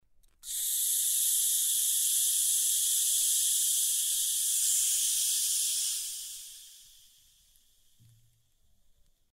Звуки утечки
На этой странице собраны различные звуки утечек: от капающей воды до шипящего газа.